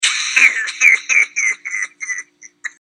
Laugh Two